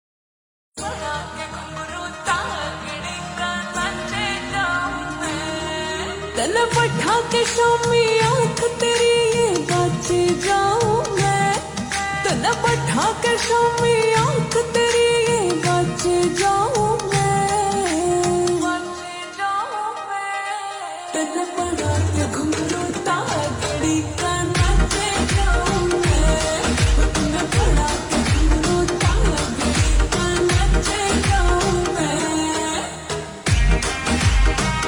Haryanvi Songs